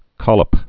(kŏləp)